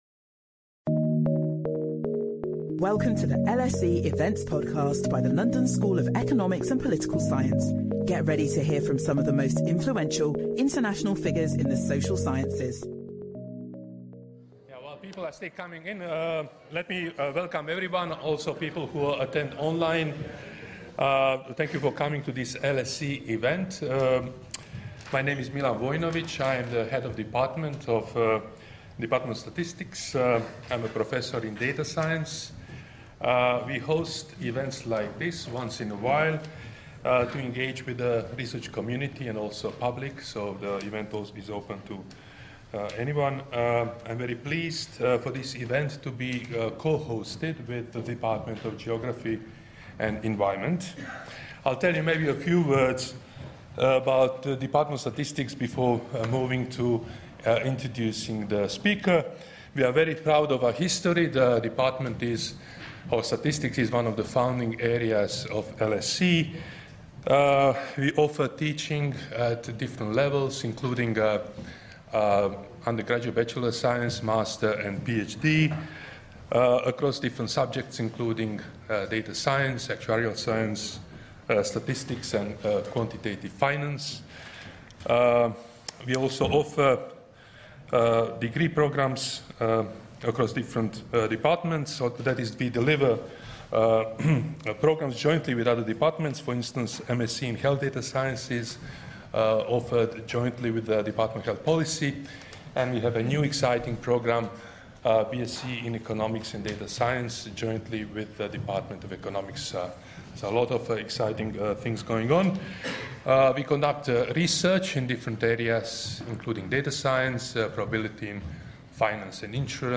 This lecture explores a period of very high productivity growth in history, WWII, to understand the sources of productivity growth generally.